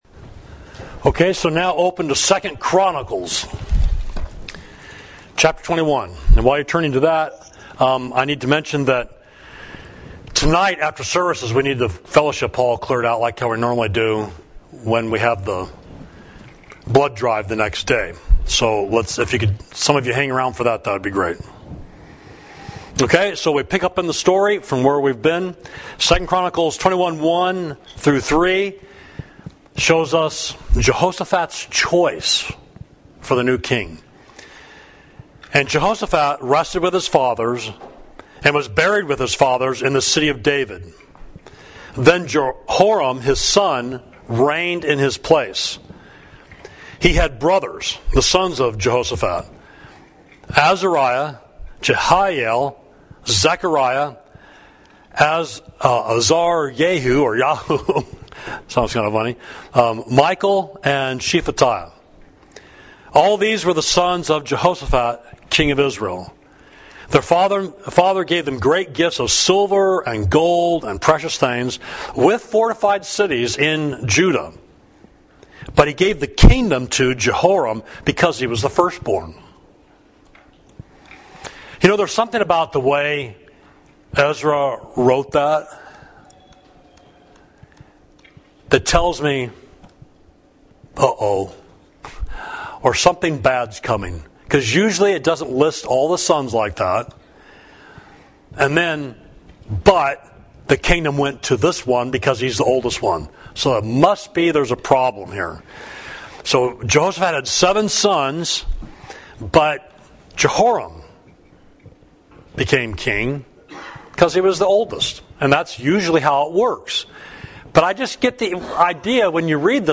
Sermon: What Evil Alliances Will Do – Savage Street Church of Christ